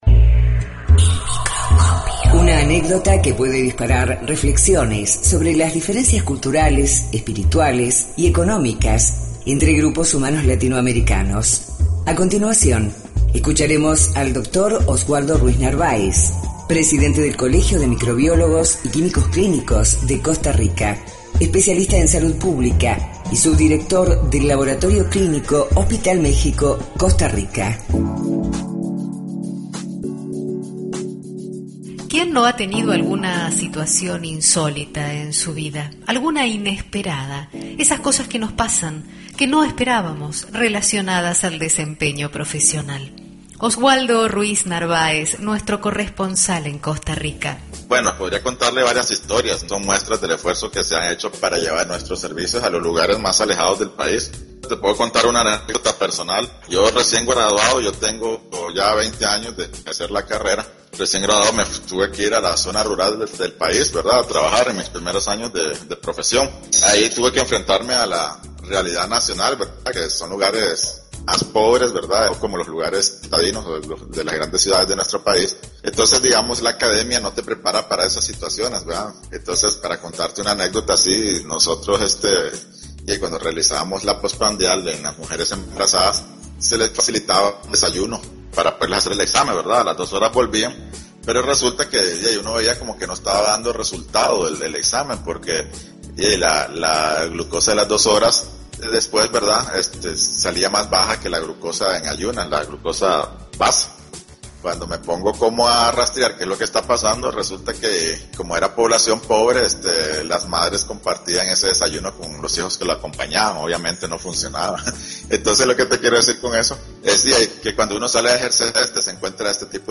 Entrevista homenaje a Clodomiro Picado, pionero de la Bioquímica en Costa Rica (26/02/2014)